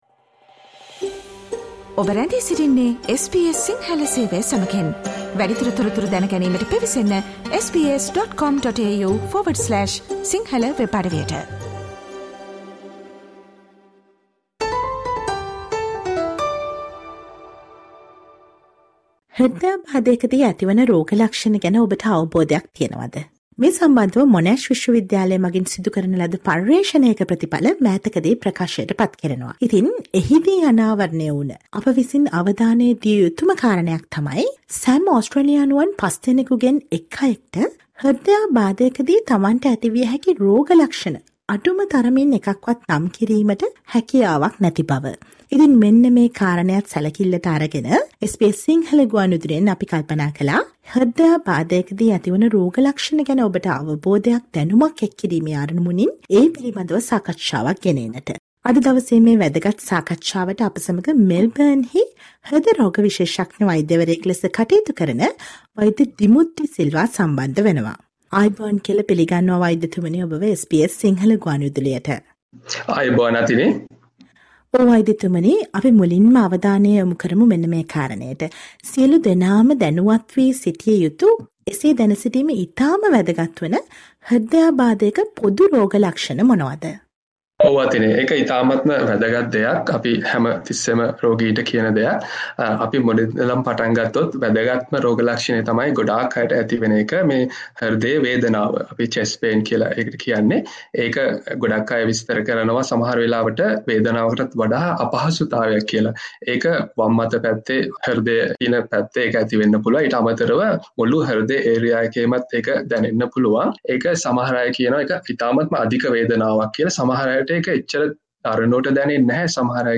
Listen to the SBS Sinhala radio interview